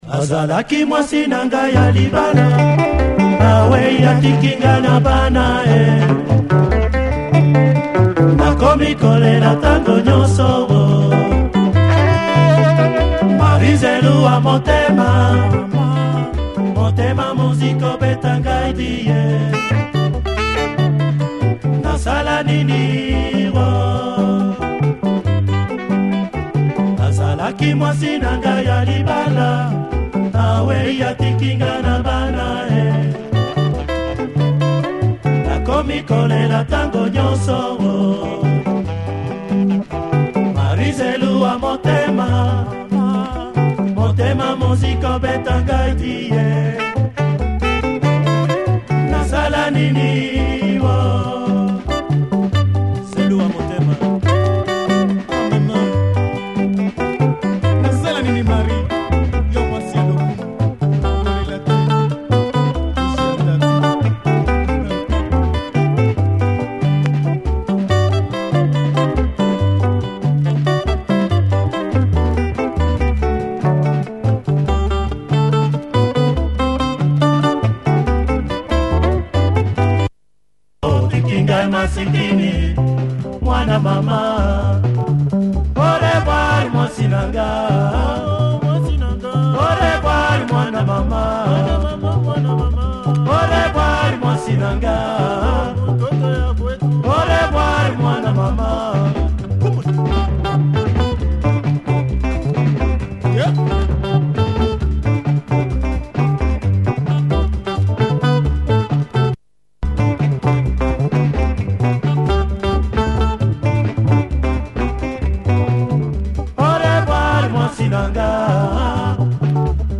lingala